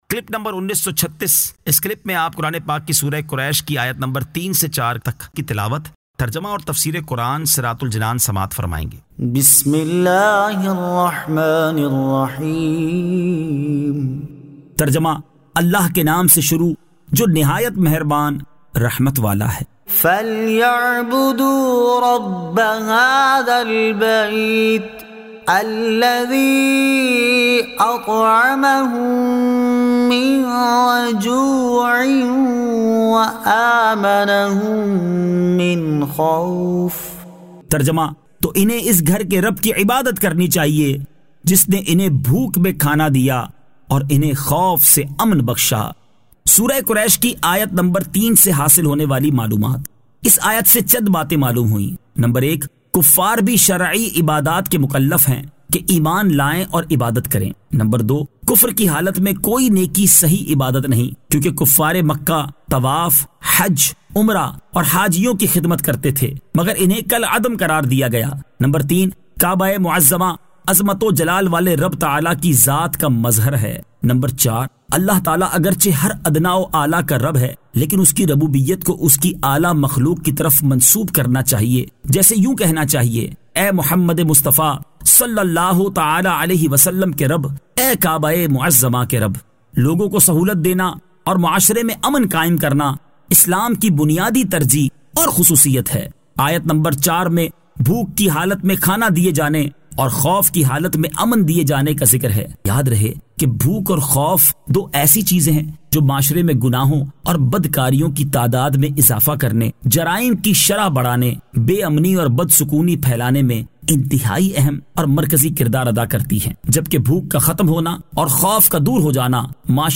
Surah Quraish 03 To 04 Tilawat , Tarjama , Tafseer